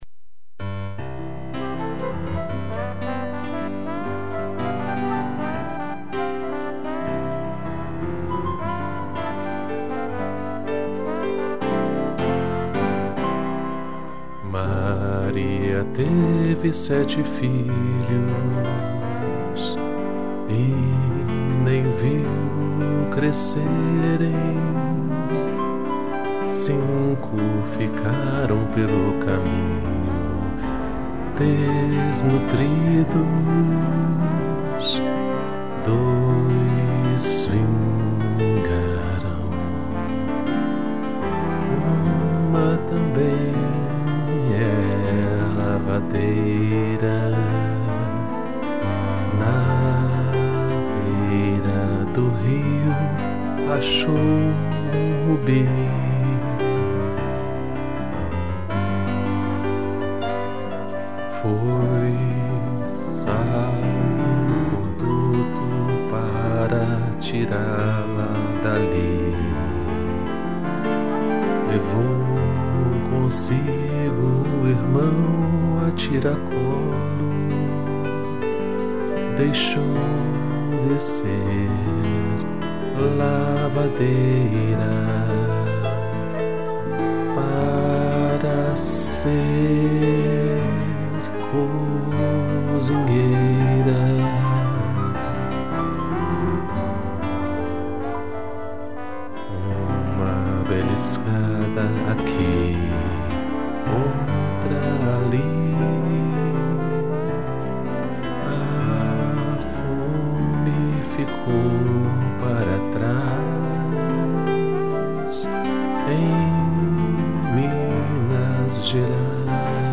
piano, violão, flauta e trombone